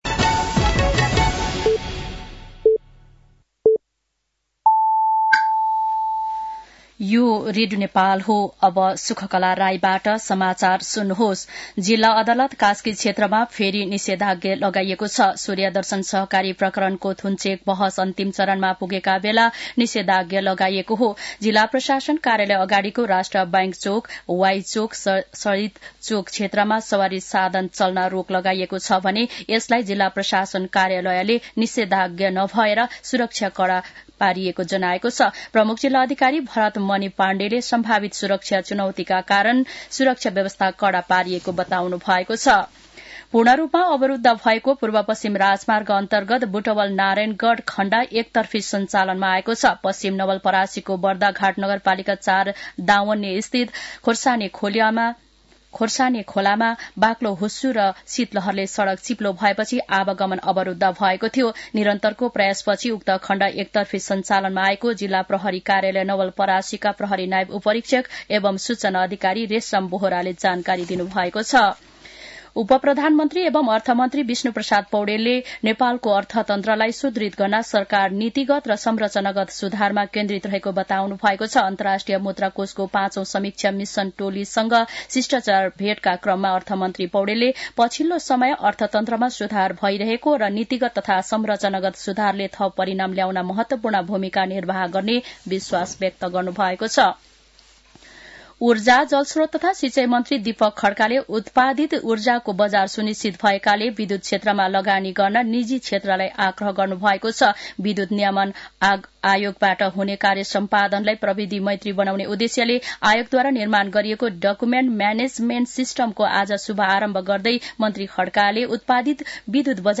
साँझ ५ बजेको नेपाली समाचार : २५ पुष , २०८१
5-pm-nepali-news-9-24.mp3